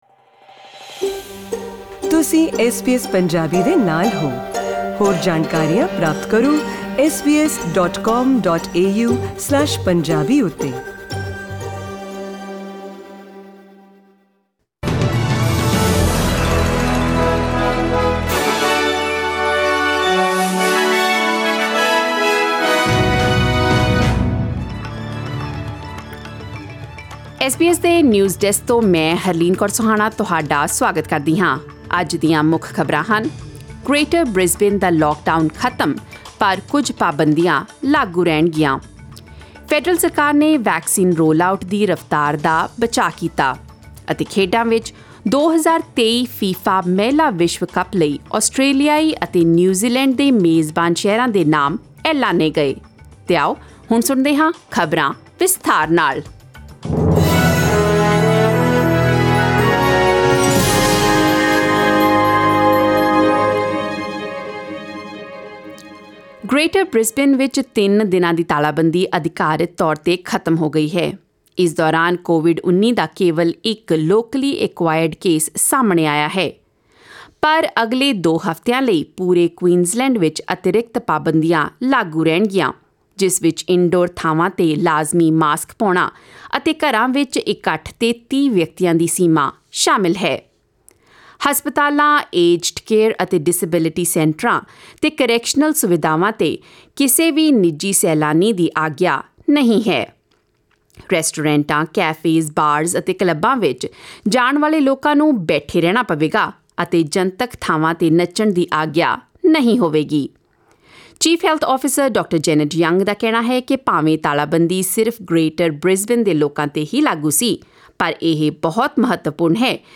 Click on the player at the top of the page to listen to the news bulletin in Punjabi.